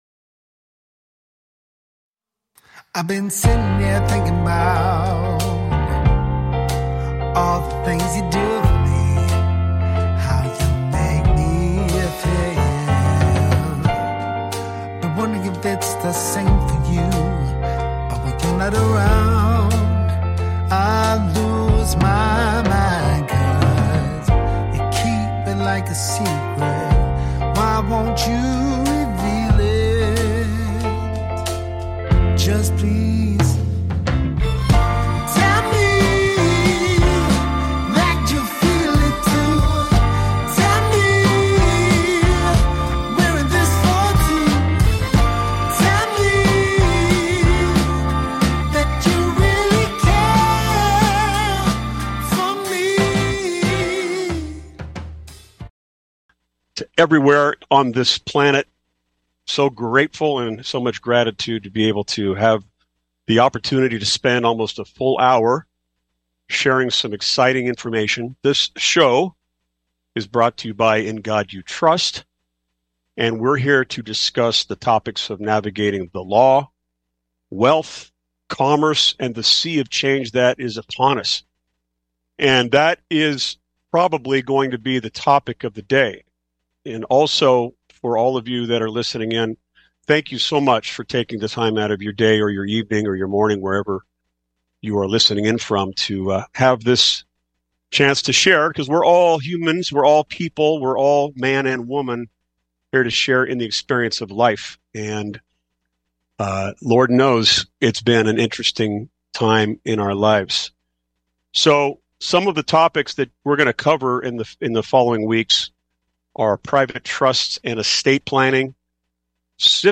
Audience Engagement: The show will feature engaging discussions and allow listeners to call in with questions.